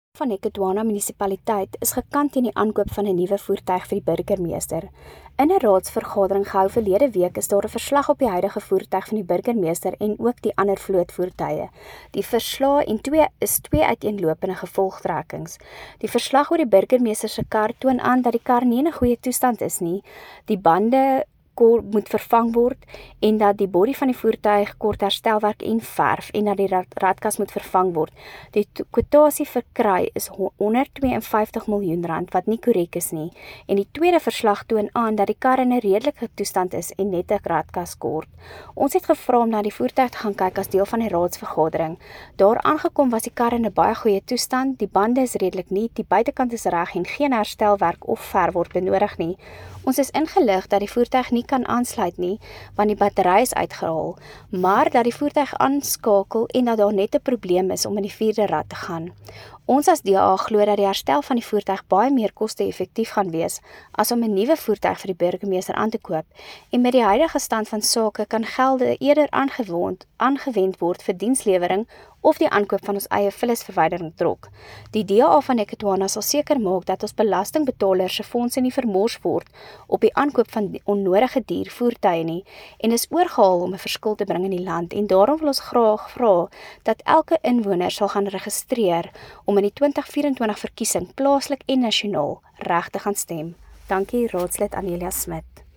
Afrikaans soundbites by Cllr Anelia Smit.